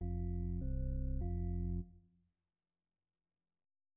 \relative c { \clef bass \set Staff.midiInstrument = "drawbar organ" f c f }
orgonahangja két oktávval magasabb, mint kellene a himnusz zenekari kottájában.